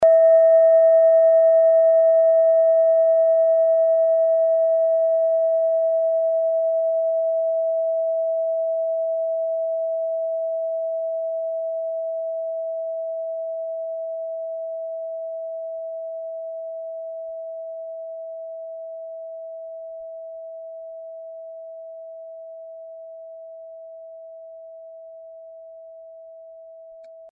Kleine Klangschale Nr.8
Diese Klangschale ist eine Handarbeit aus Bengalen. Sie ist neu und ist gezielt nach altem 7-Metalle-Rezept in Handarbeit gezogen und gehämmert worden.
(Ermittelt mit dem Minifilzklöppel)
Diese Frequenz kann bei 224Hz hörbar gemacht werden, das ist in unserer Tonleiter nahe beim "A".
kleine-klangschale-8.mp3